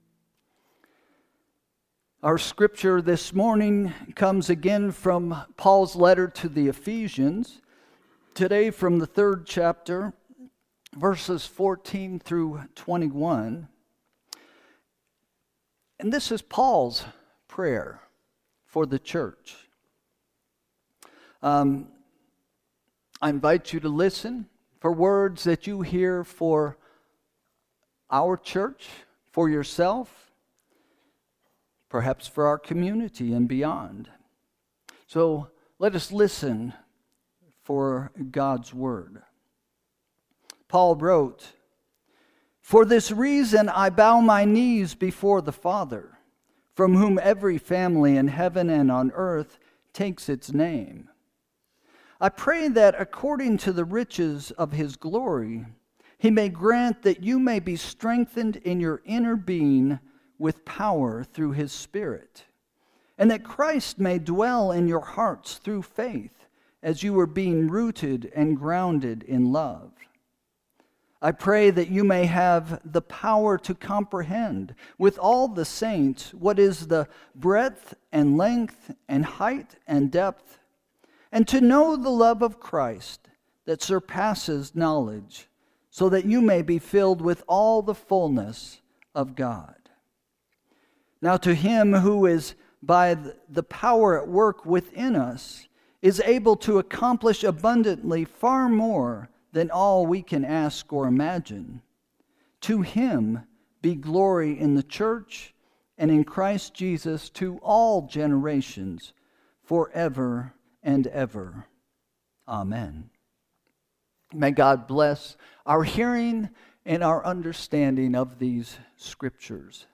Sermon – September 21, 2025 – First Christian Church